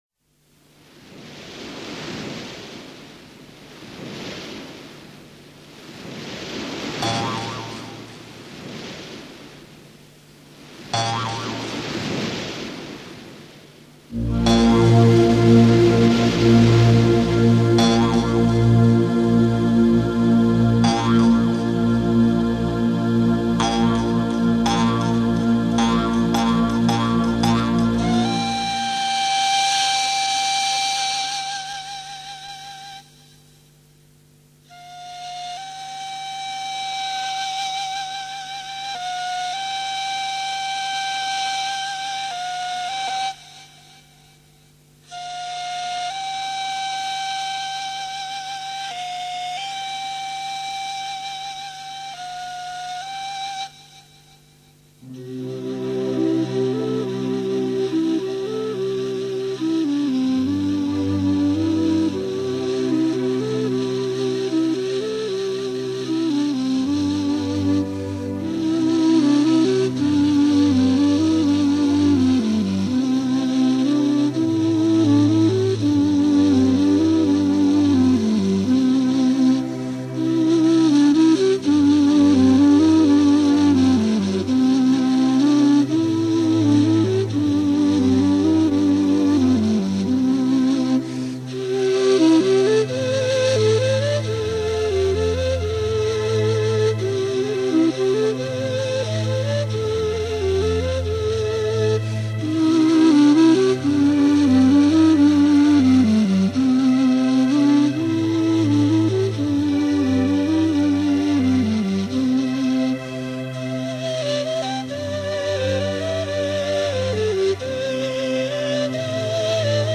Музыка длинная, это танец - почти 6 минут, на конкурсы не проходит по хронометражу.
У той музыки,которую я выложила (она начинается с шума воды) удаляем кусок с 24 сек по 43,получится композиция 5 мин с небольшим.Начало до 23 сек остается, а потом идет уже с 43 сек.